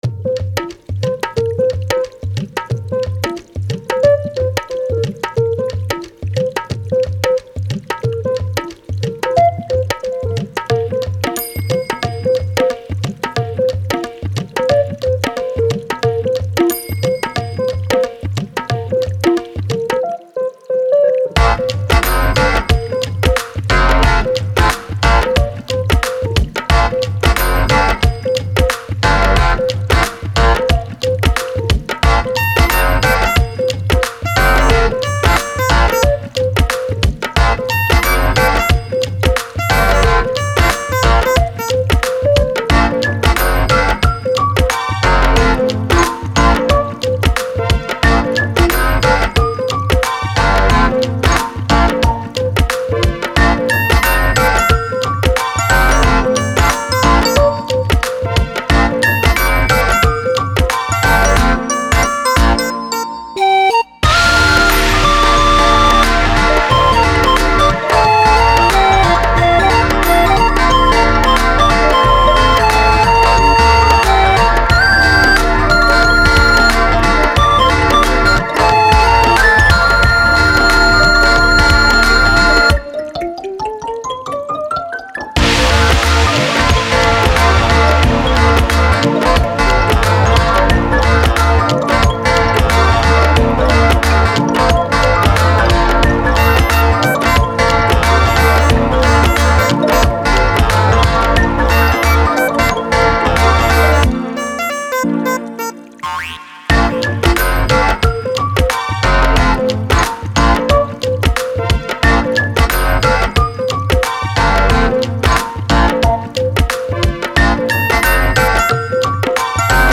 Frog = Rainforest and a bunch of tribal drums
I kinda like how funky this turned out :o